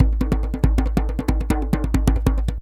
DJEM.GRV03.wav